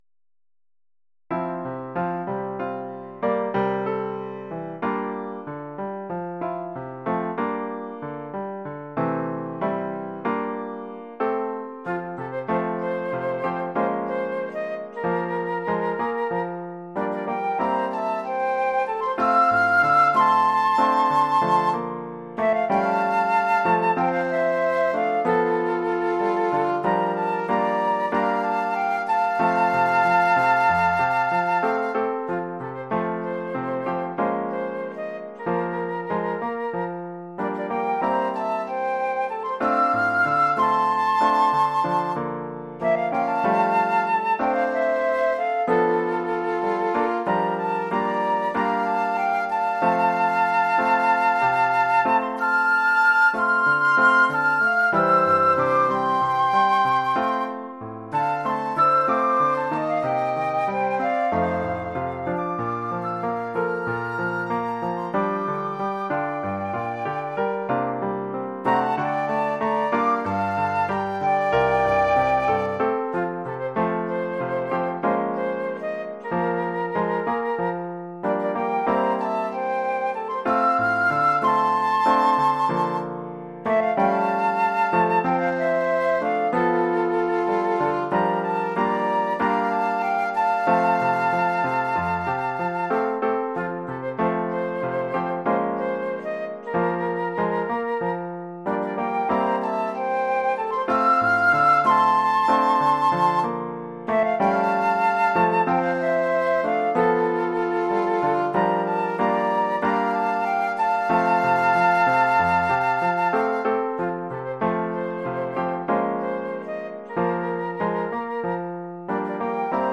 Oeuvre pour deux flûtes et piano.